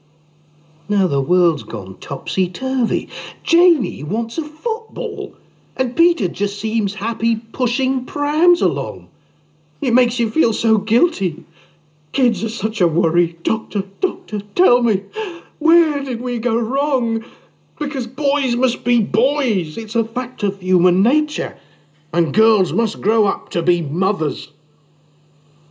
Boyswillbeboys_stanza4.mp3